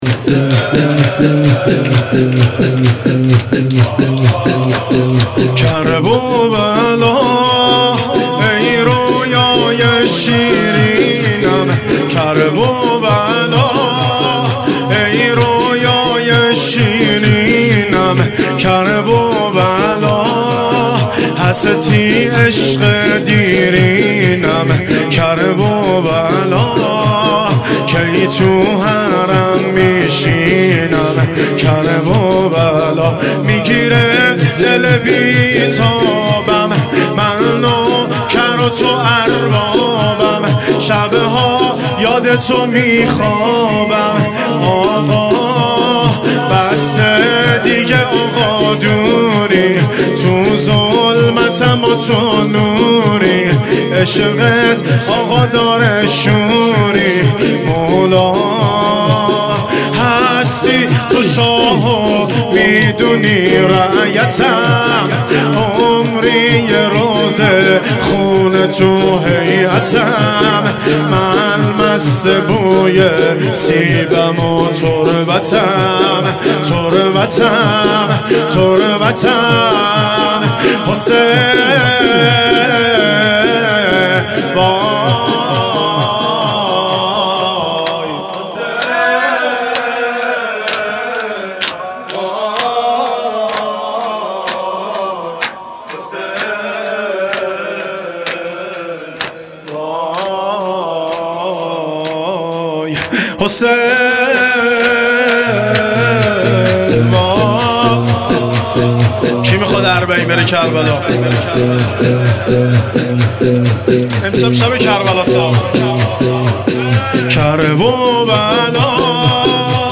شب اول محرم 1400
شور